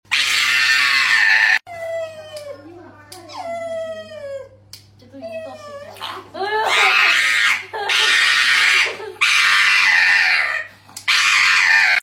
Pugs can sound like this?! sound effects free download